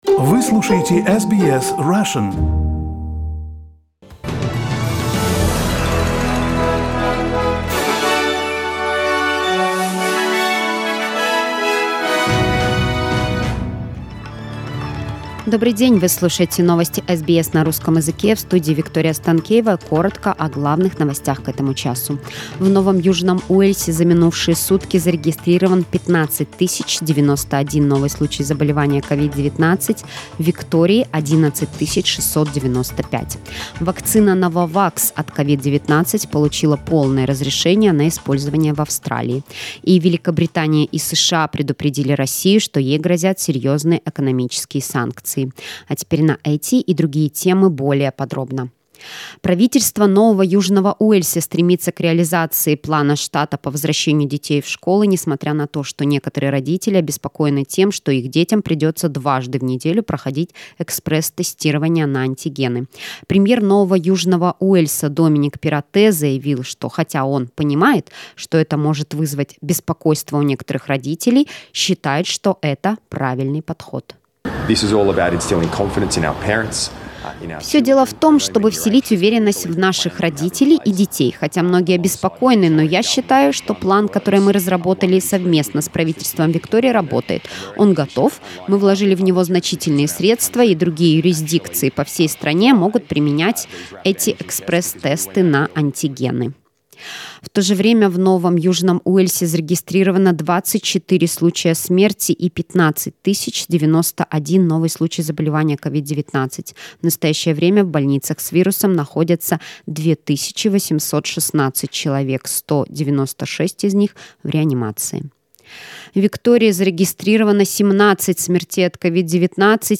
Listen to the latest news headlines in Australia from SBS Russian.